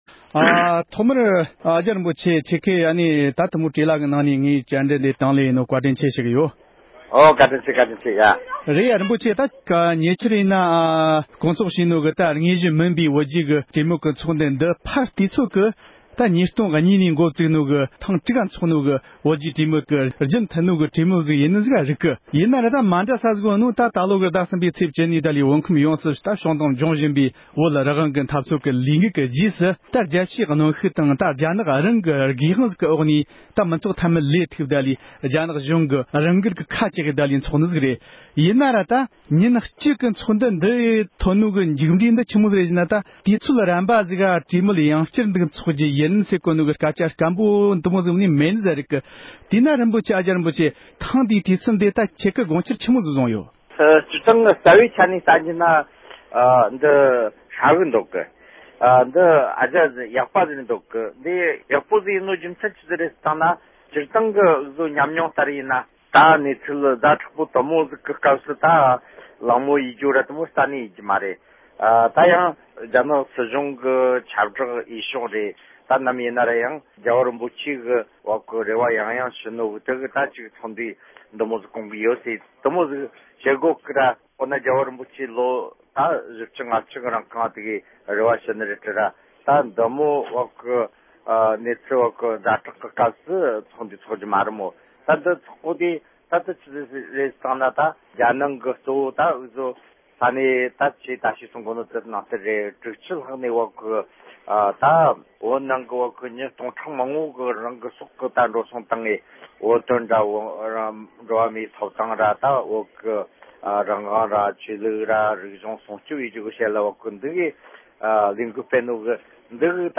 བཅར་འདྲི་བྱས་ཡོད་པར་གསན་རོགས་གནང༌།།